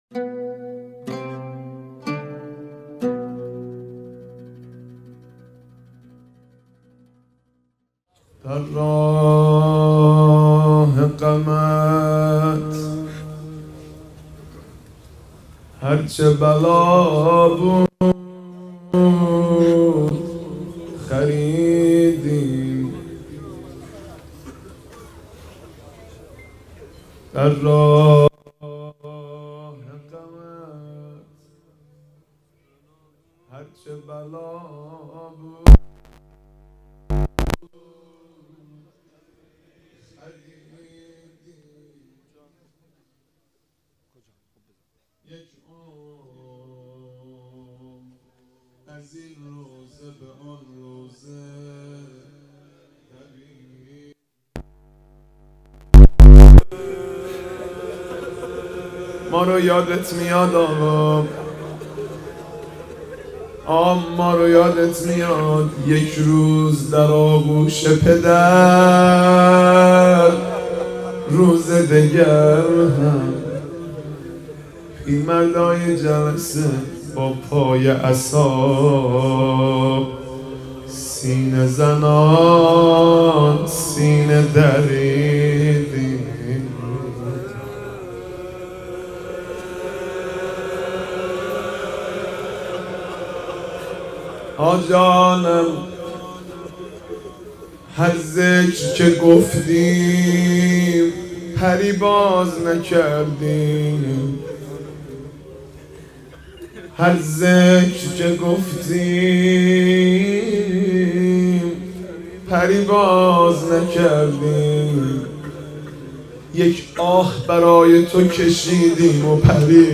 روضه خوانی
اجتماع زائران آذری‌زبان اربعین
مسیر نجف به کربلا، عمود 1120 موکب خادم الحسین (علیه السلام)